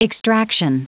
Prononciation du mot extraction en anglais (fichier audio)
Prononciation du mot : extraction